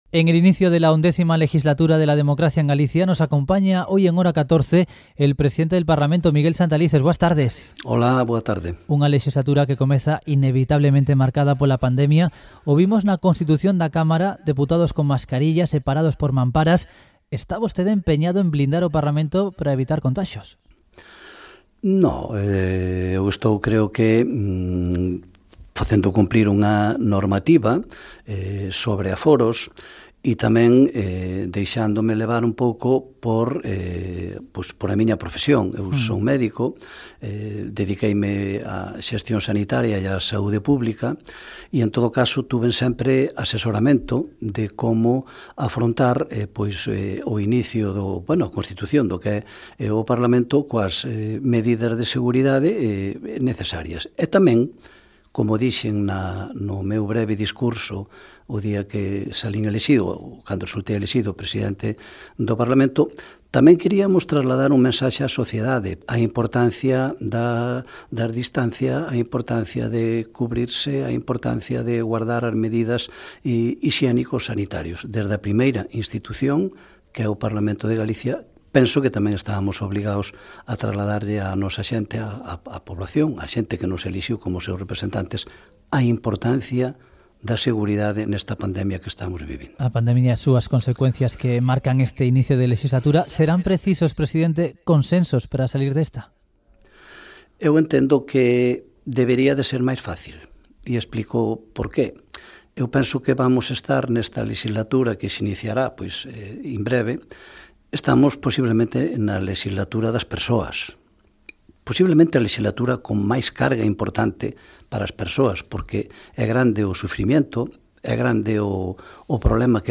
Entrevista en el programa "Hora 14 Galicia" de la Cadena Ser
Entrevista Miguel Santalices Cadena Ser.mp3